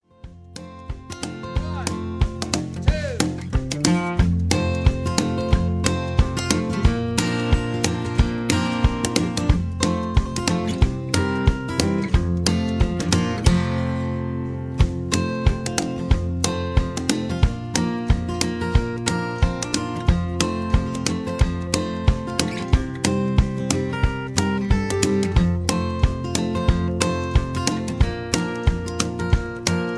backing tracks, karaoke, sound tracks, studio tracks, rock